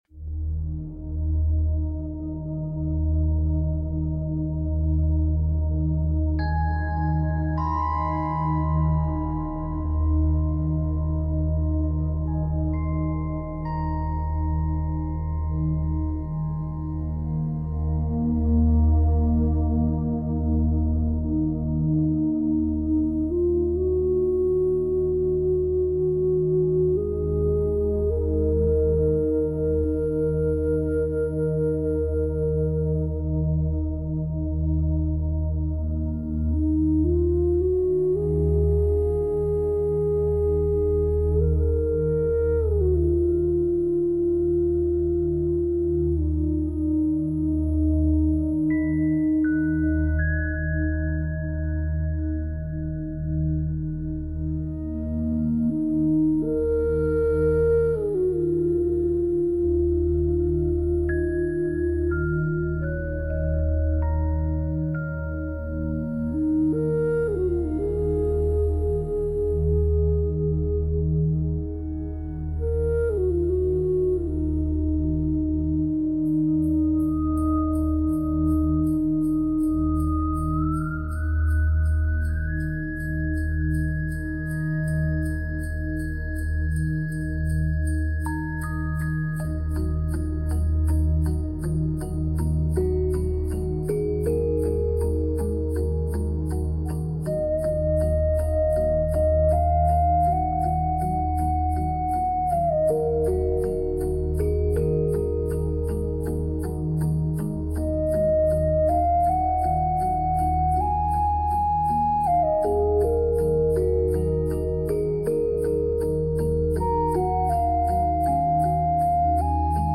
Frequency: 741 Hz (Throat Chakra)
No vocals | Long-form ambient